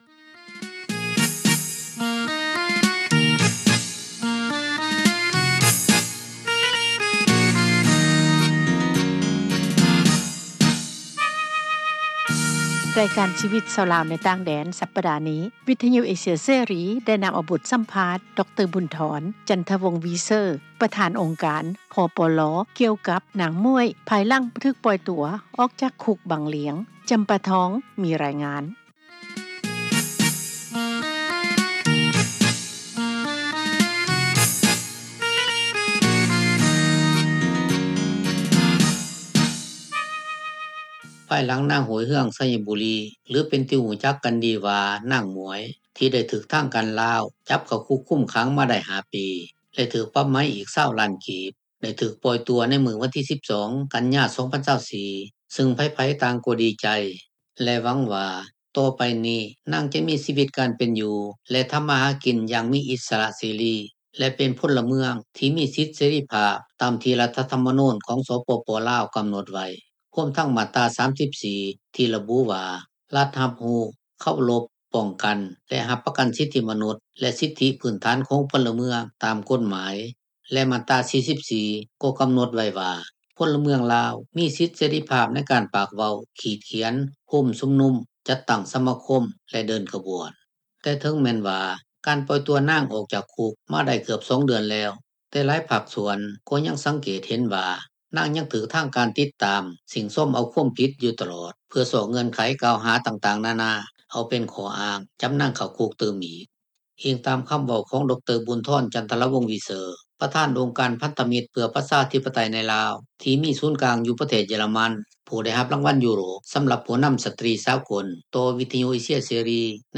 ບົດສໍາພາດ